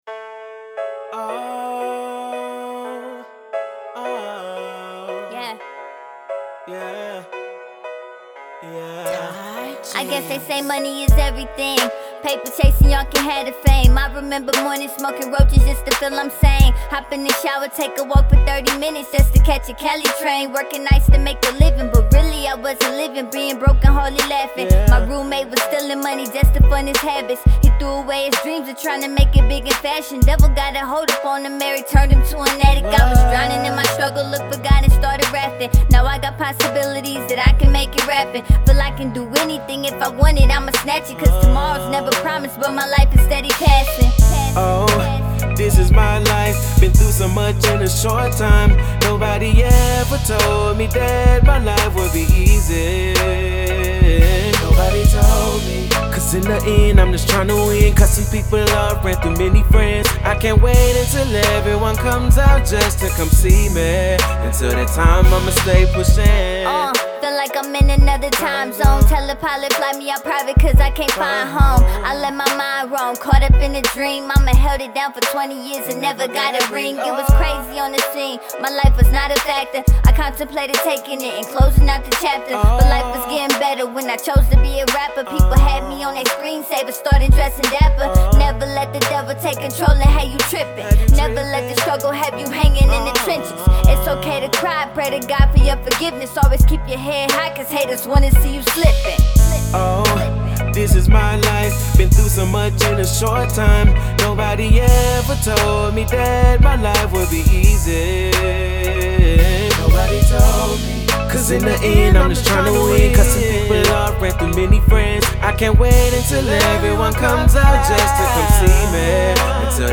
Indie
Description : Raw Gritty Midwest Music. Real Ohio Rap!!!